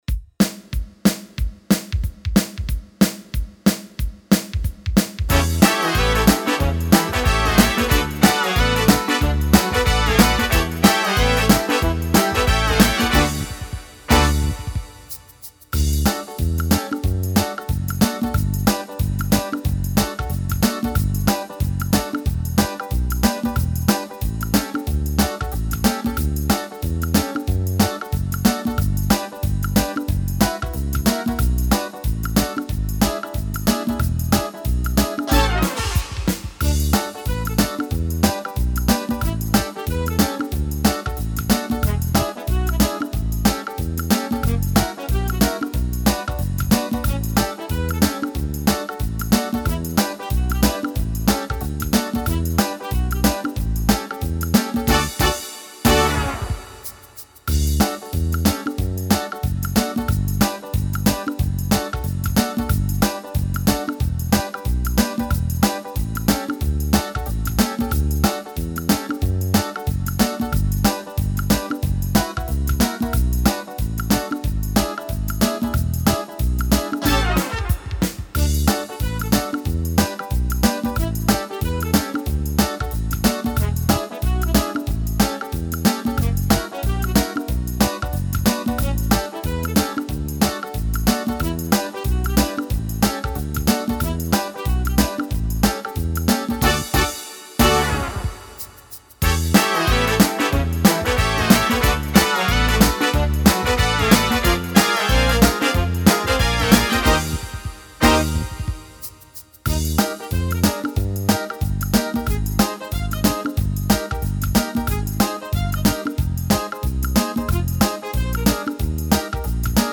Слушать минус
Скачать минус детской песни